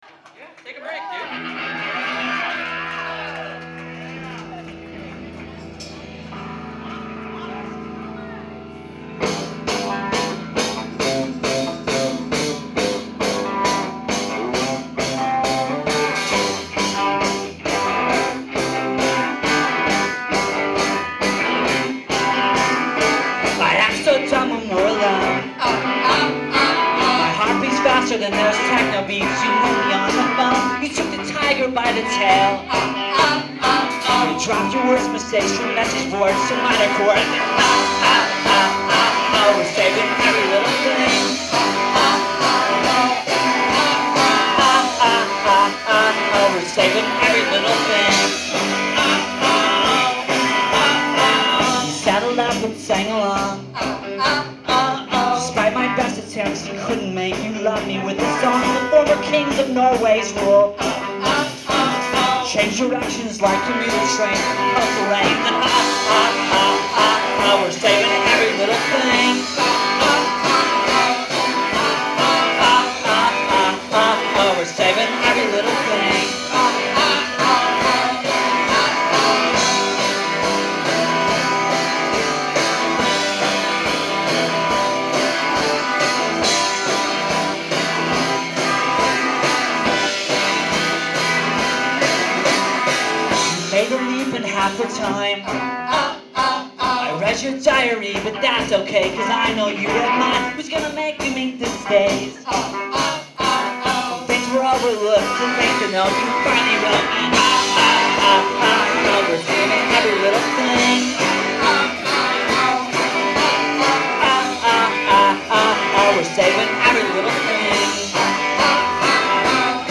Live at PA’s Lounge
in Somerville, MA